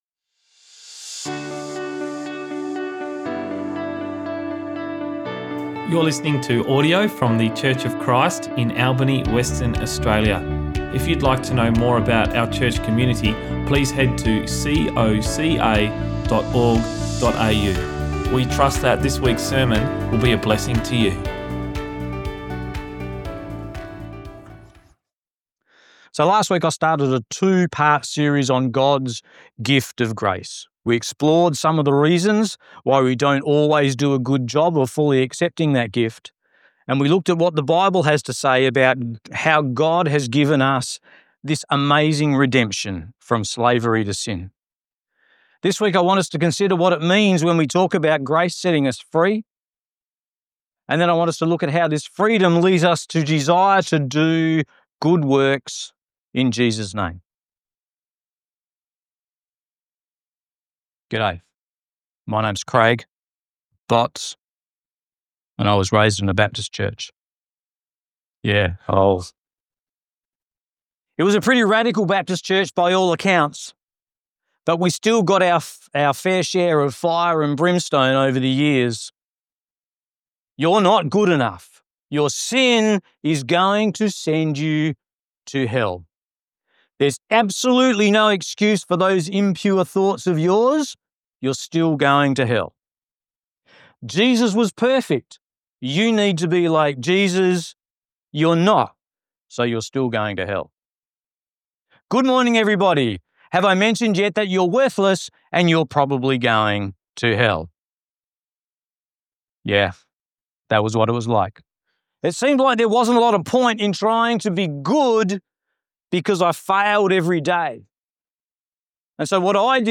Sermons | Church of Christ Albany